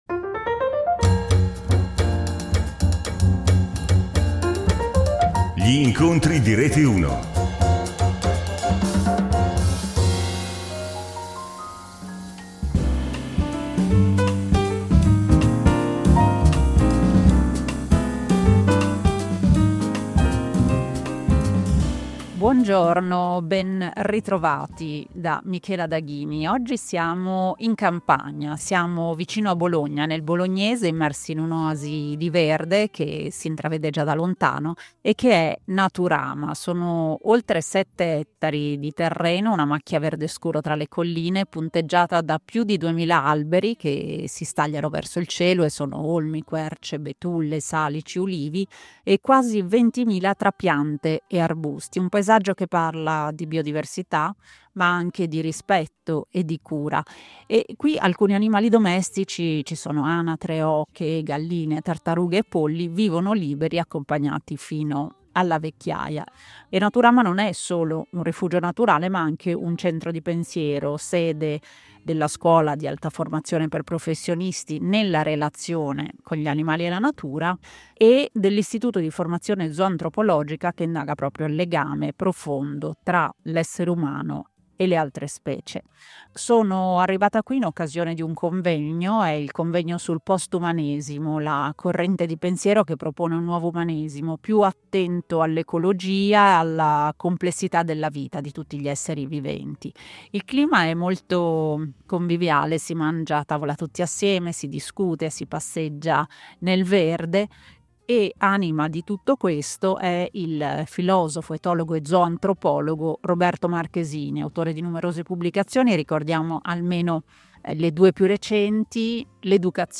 Questa volta andiamo nel Bolognese, immersi in un’oasi di verde.
Siamo qui in occasione del convegno sul Postumanesimo, la corrente che invita a ripensare l’umano, aprendolo all’ecologia, alla complessità della vita, alla pluralità degli esseri viventi. Un cammino che ci chiede di ascoltare, di accogliere, di riconoscere l’altro — umano e non umano — come parte di un tutto.